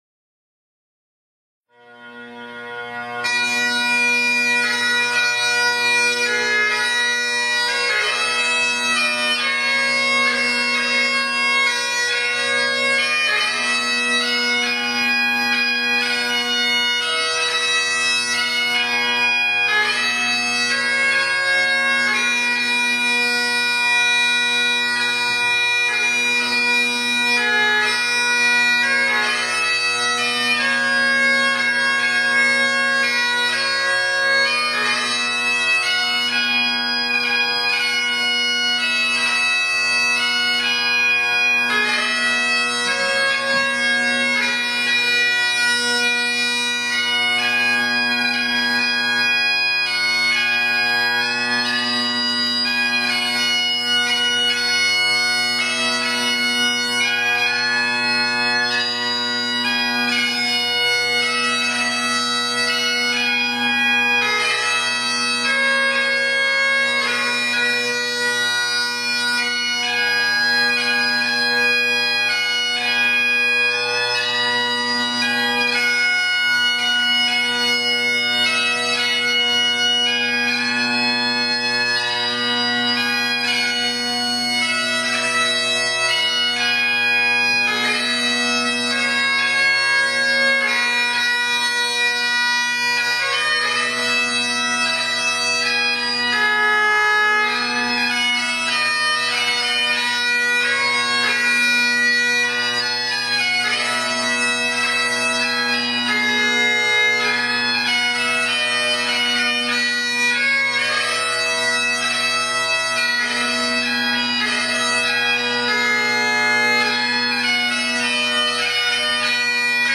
Pour une interprétation de Scots wha hae à la cornemuse et sans percussion.